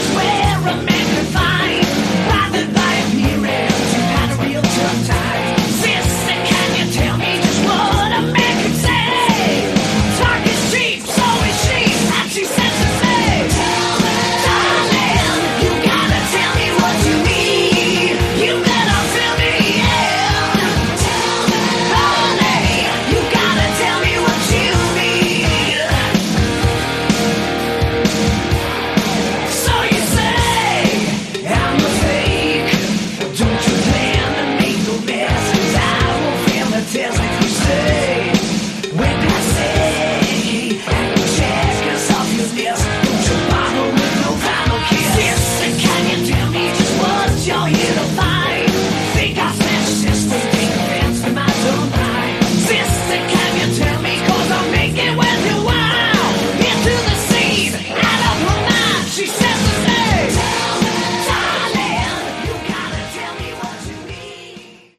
Category: Glam
vocals
guitars
bass
drums